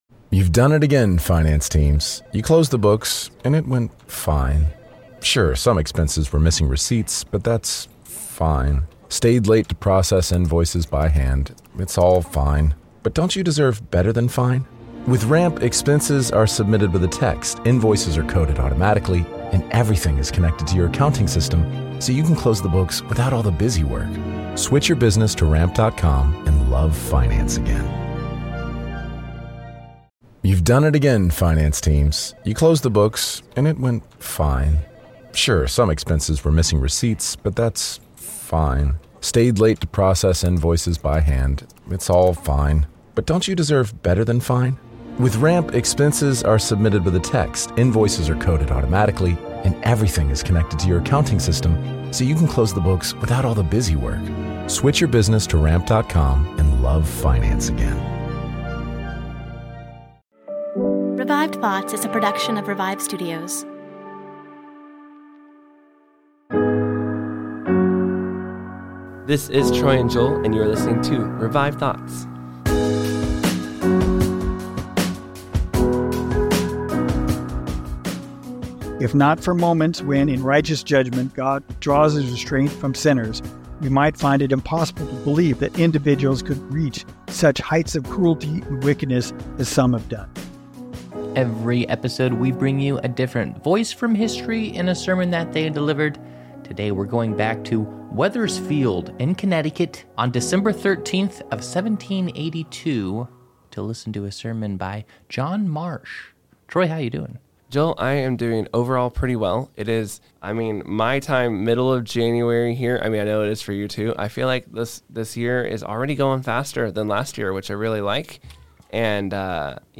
But this sermon was preached after a tragic murder-suicide in Connecticut in the late 18th century. This is the funeral sermon of this tragedy that looks at why it happened and what can be learned from it.